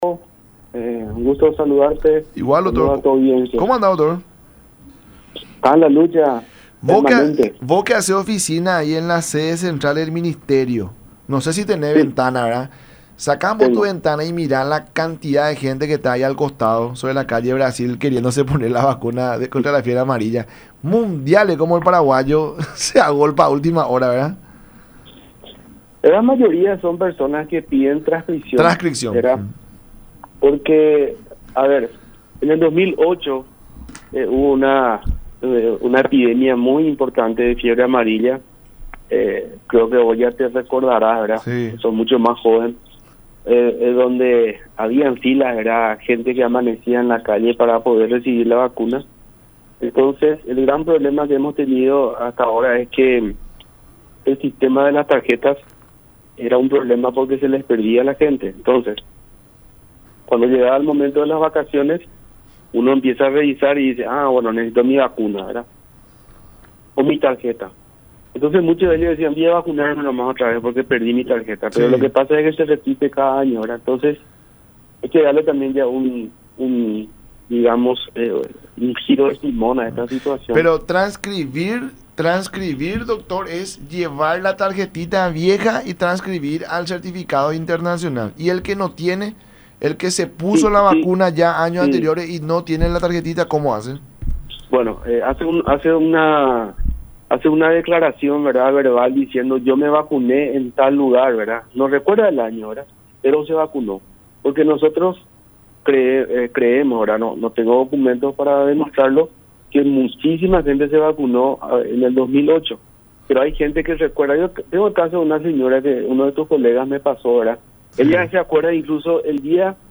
“En el 2008 hubo una epidemia muy importante de fiebre amarilla. Nosotros habíamos comenzado en ese año una importante campaña de vacunación contra la fiebre amarilla, pero como en ese entonces regía el sistema de tarjetas de vacunación, a mucha gente se le perdió”, dijo Martínez en charla con La Mañana De Unión por Unión TV y radio La Unión.